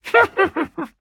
Minecraft Version Minecraft Version 1.21.4 Latest Release | Latest Snapshot 1.21.4 / assets / minecraft / sounds / entity / witch / ambient4.ogg Compare With Compare With Latest Release | Latest Snapshot